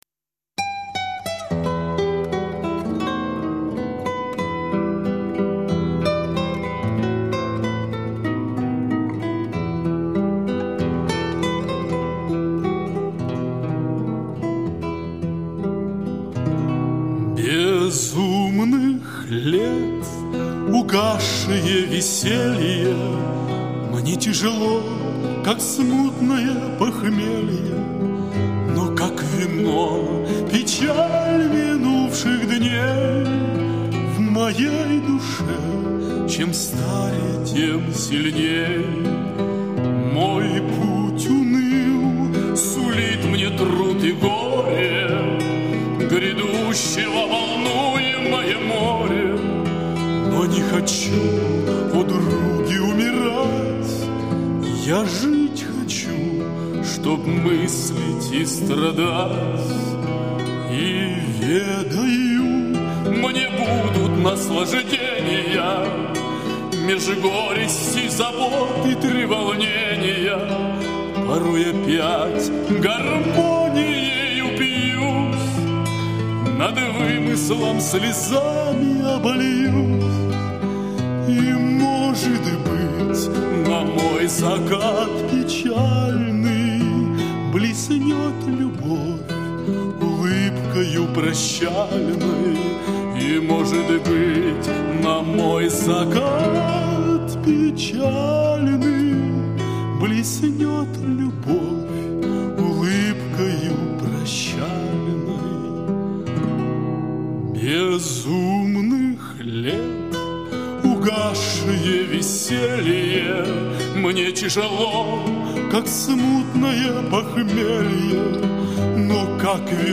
Вы услышите старинные русские романсы, а также романсы, написанные самим исполнителем на стихи поэтов золотого и серебряного века.
гитара, вокал